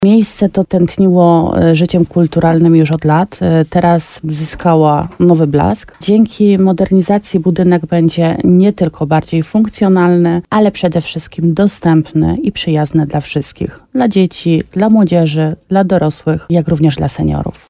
– Jest to długo wyczekiwana inwestycja – mówi wójt gminy Łabowa, Marta Słaby.